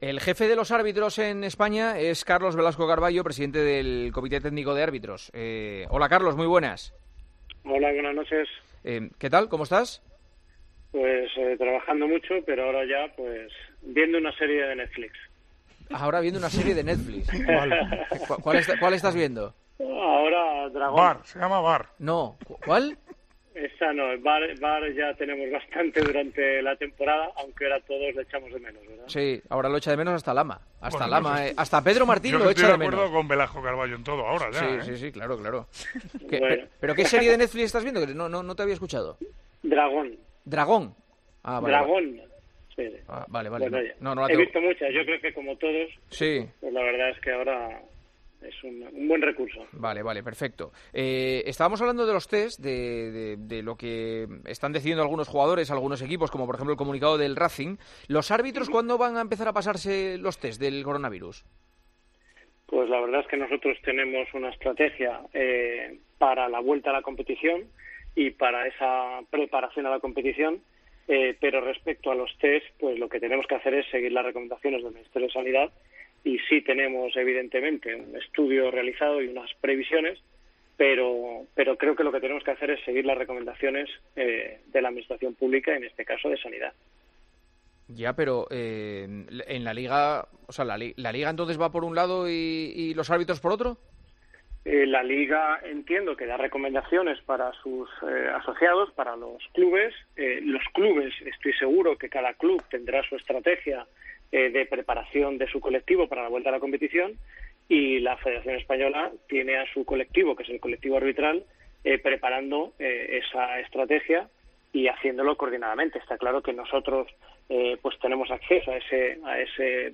AUDIO - ESCUCHA LA ENTREVISTA AL PRESIDENTE DEL CTA, CARLOS VELASCO CARBALLO, EN EL PARTIDAZO DE COPE